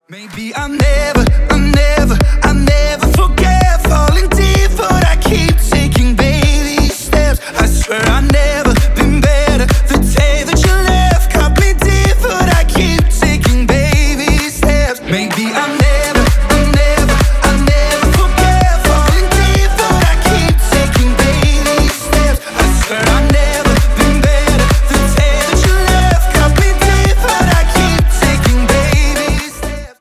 Клубные рингтоны